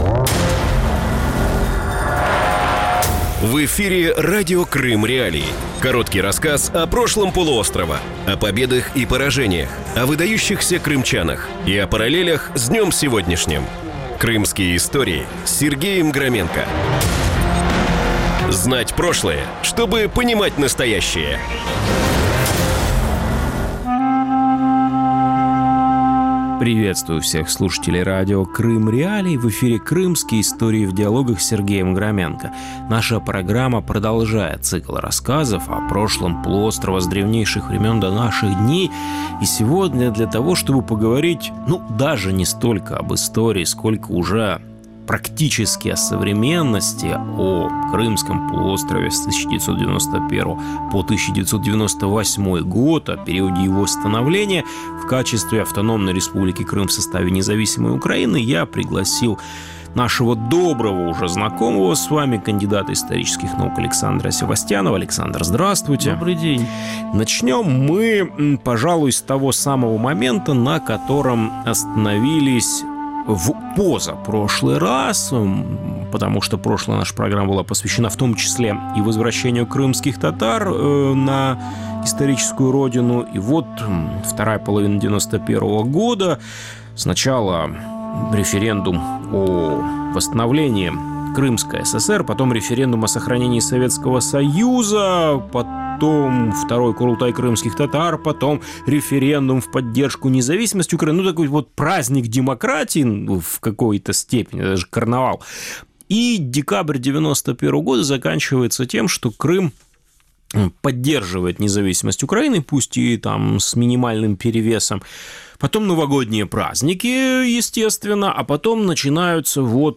Новый цикл Крымских.Историй в диалогах рассказывает об истории Крыма с древнейших времен до наших дней.